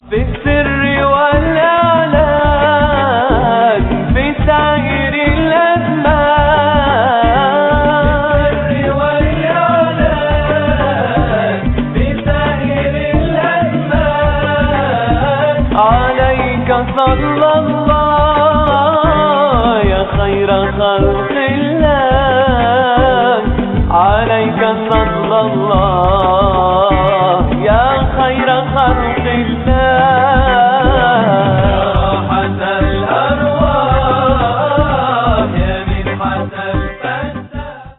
اناشيد